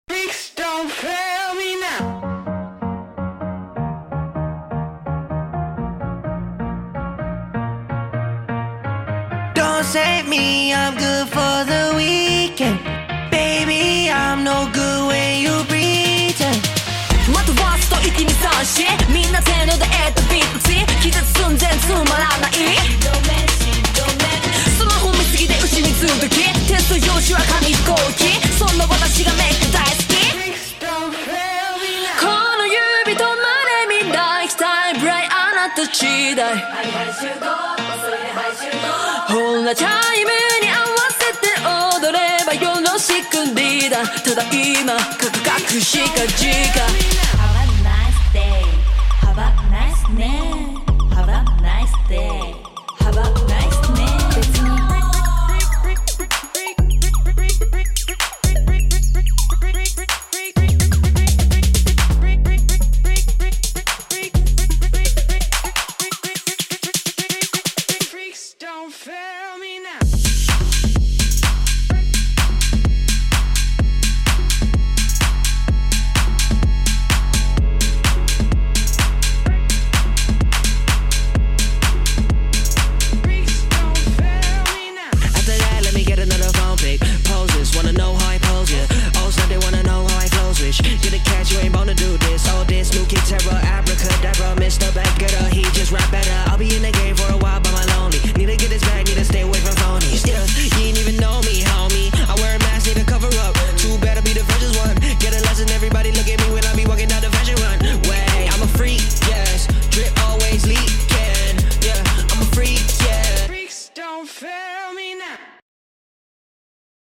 BPM127-127
Audio QualityCut From Video